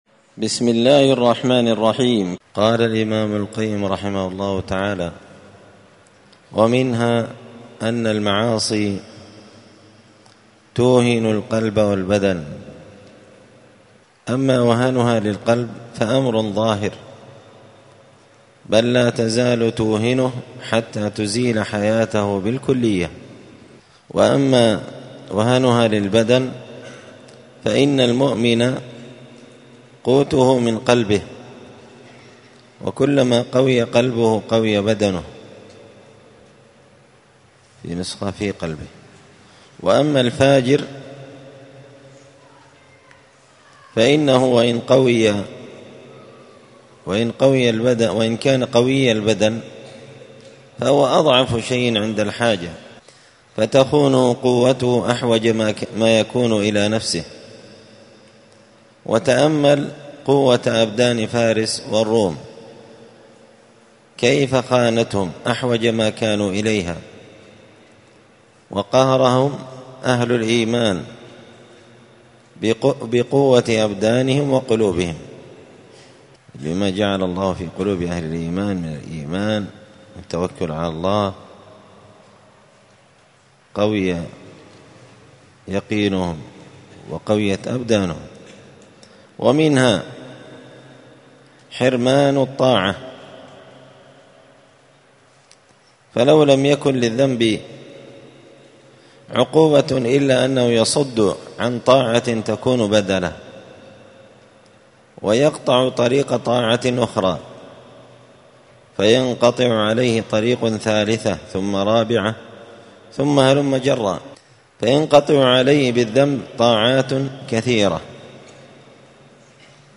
السبت 18 جمادى الأولى 1445 هــــ | الداء والدواء للإمام ابن القيم رحمه الله، الدروس، دروس الآداب | شارك بتعليقك | 70 المشاهدات
مسجد الفرقان قشن_المهرة_اليمن